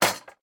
small_destroy5.ogg